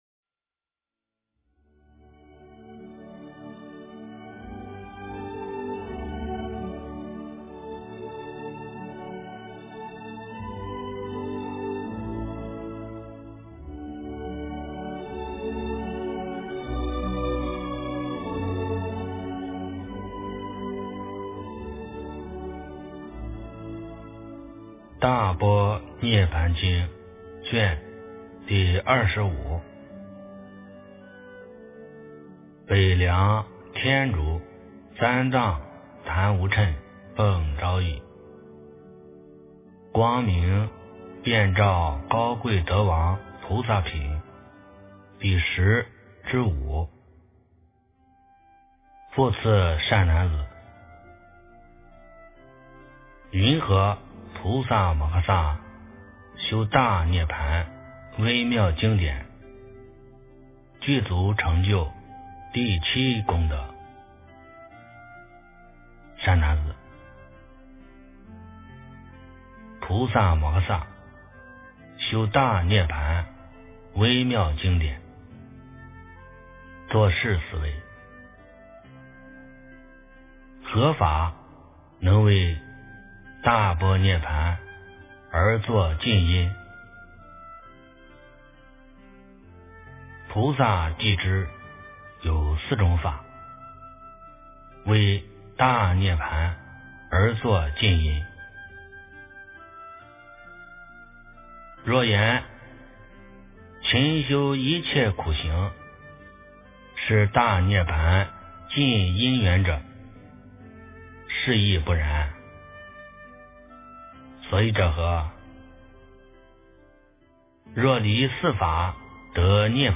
诵经
佛音 诵经 佛教音乐 返回列表 上一篇： 大般涅槃经23 下一篇： 佛说四十二章经 相关文章 莲花生大士心咒-Guru Rinpoche Mantra--Deva Premal 莲花生大士心咒-Guru Rinpoche Mantra--Deva Premal...